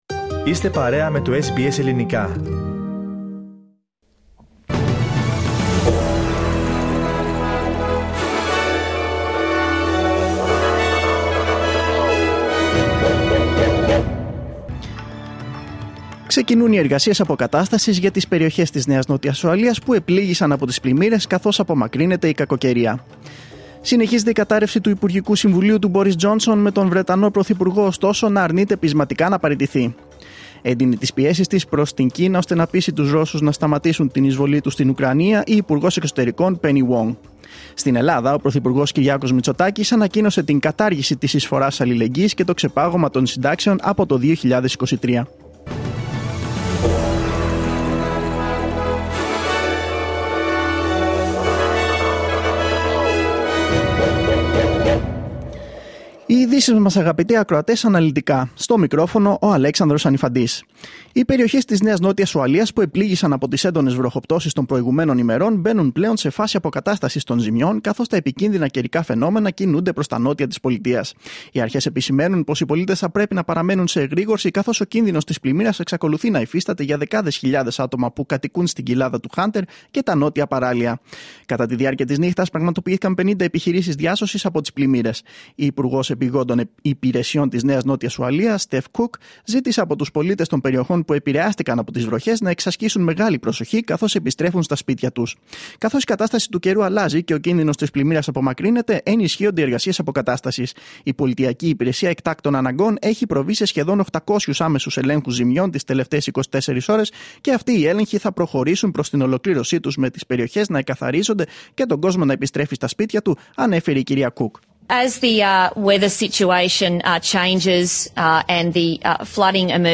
Δελτίο Ειδήσεων Πέμπτη 7.7.2022